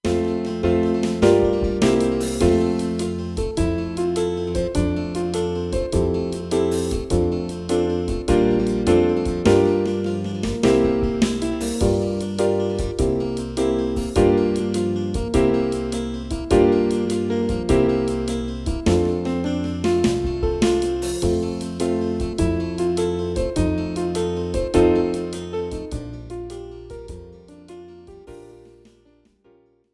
instrumental backing track cover
• Without Backing Vocals
• No Fade